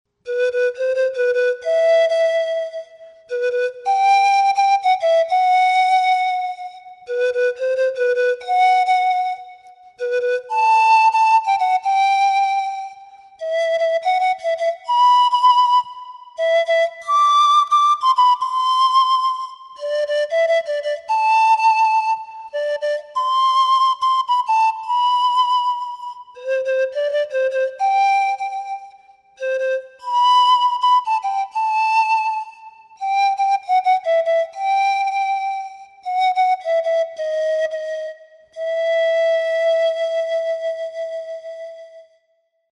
Панфлейта UU-15 левосторонняя
Панфлейта UU-15 левосторонняя Тональность: G
Материал: пластик ABS. Диапазон - две октавы (соль первой – соль третьей), строй диатонический (соль мажор).